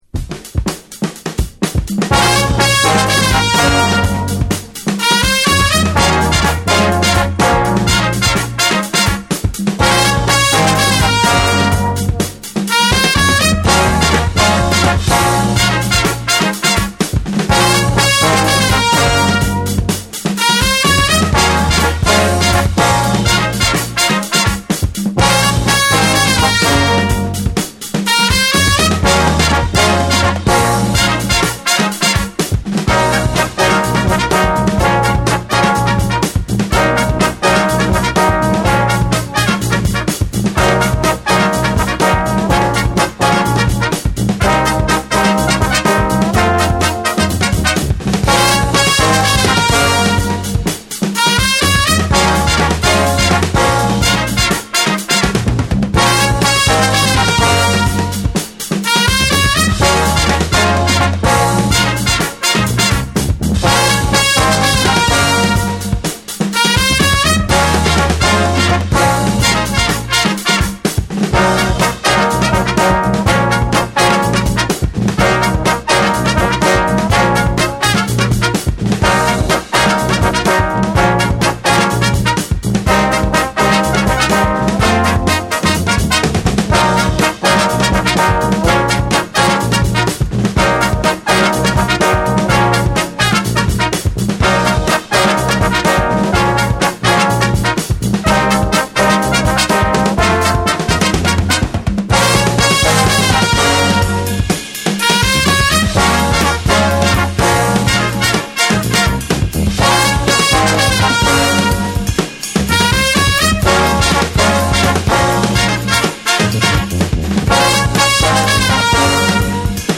SOUL & FUNK & JAZZ & etc / BREAKBEATS